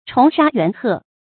蟲沙猿鶴 注音： ㄔㄨㄙˊ ㄕㄚ ㄧㄨㄢˊ ㄏㄜˋ 讀音讀法： 意思解釋： 舊時比喻戰死的將士。